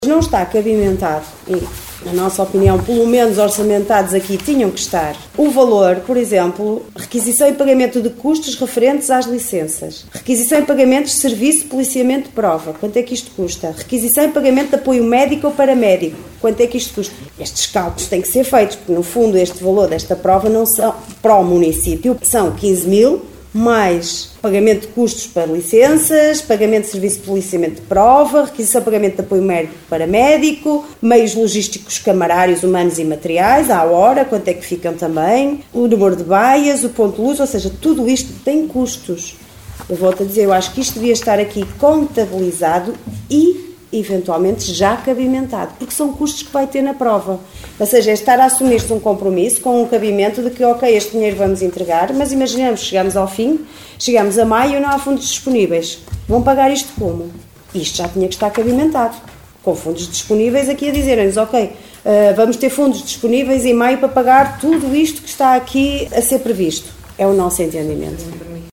Excertos da última reunião de câmara, no passado dia 7 de Fevereiro, onde foram aprovados os apoios financeiros à Associação Triatlo de Caminha para a realização do Triatlo Longo, Meia Maratona e Corrida de São Silvestre, cujos percursos e condições se mantêm, segundo os protocolos, idênticos às edições dos anos anteriores.